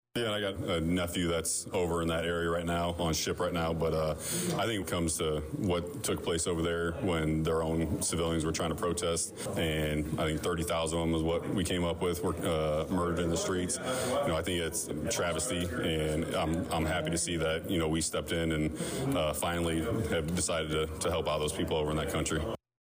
Both Schweizer and Rose were in attendance at Monday (Mar 2nd) morning’s Vermilion County Farm Bureau Legislators Meeting.